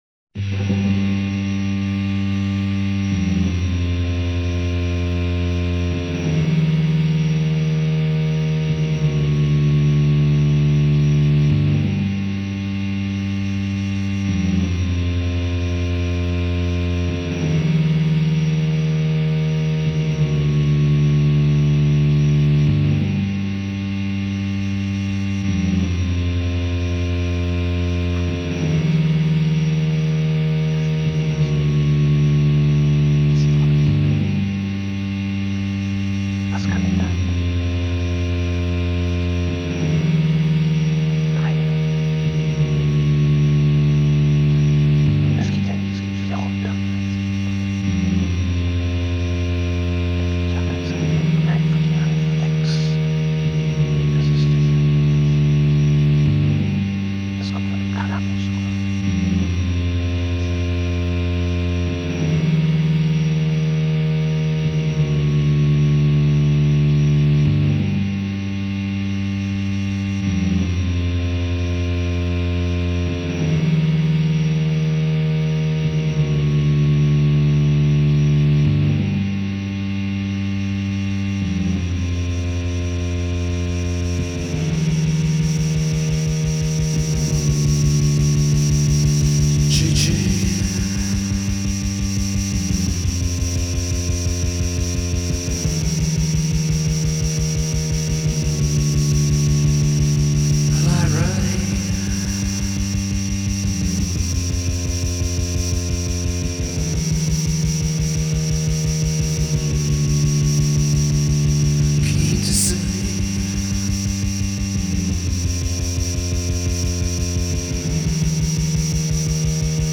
Des bris, fragments, du bruit.